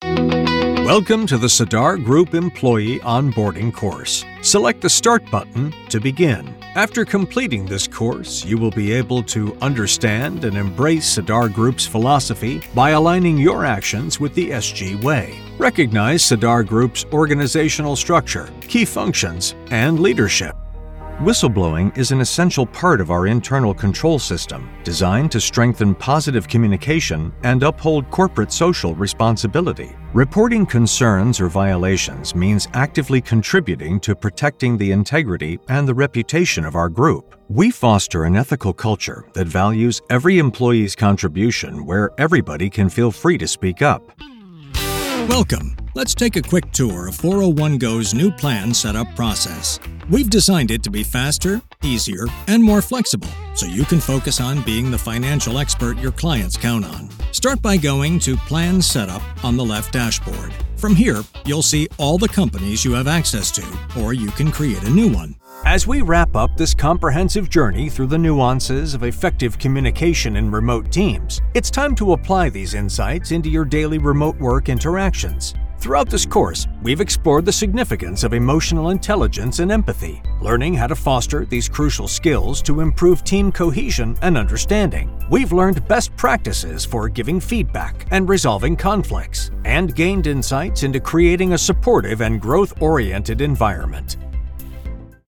E-learning
His voice has been described as Articulately Conversational.
Neumann TLM-103
Custom designed broadcast quality Whisper Room
Mediana edad